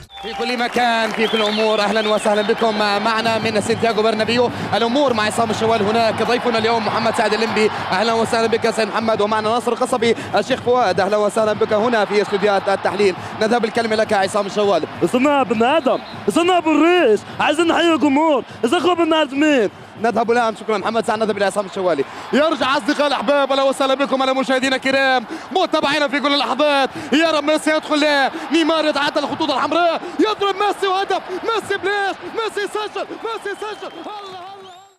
لتحميل نغمة تقليد عصام الشوالى ارسل الكود 149788 الى 1616